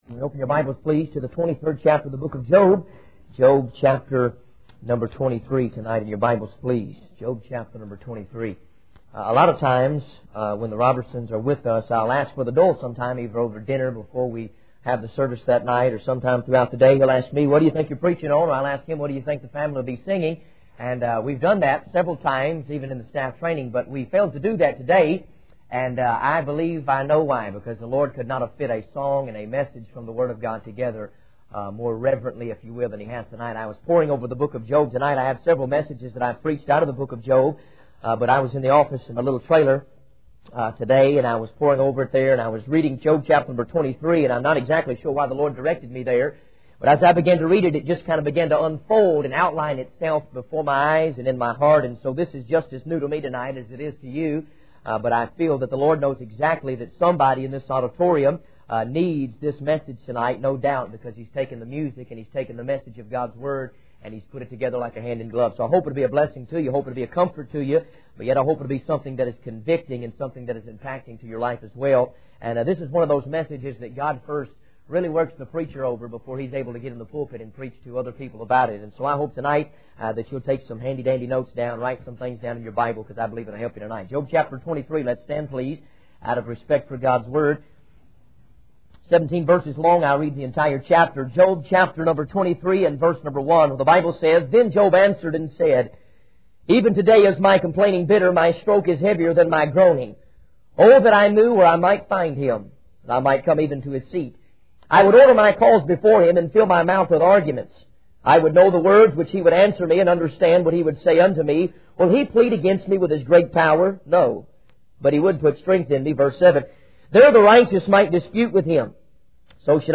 In this sermon, the preacher discusses the story of Job and the trials he faced.